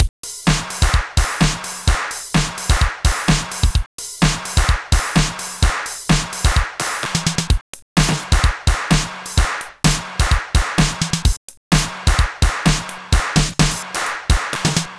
Session 11 - Mixed Beat 02.wav